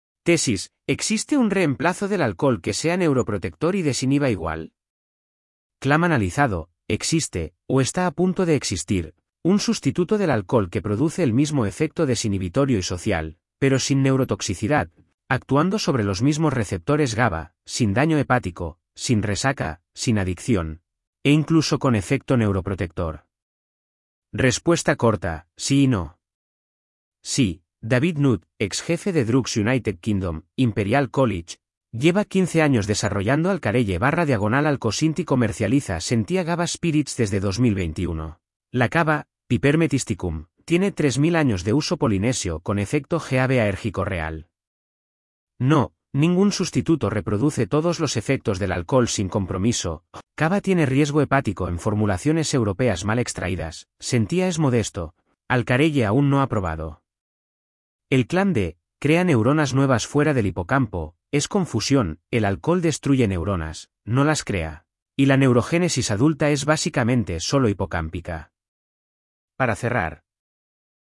Voz: Microsoft Alvaro (es-ES, neural).